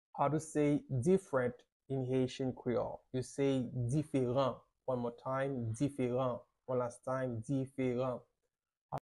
How to say "Different" in Haitian Creole - "Diferan" pronunciation by a native Haitian teacher
“Diferan” Pronunciation in Haitian Creole by a native Haitian can be heard in the audio here or in the video below: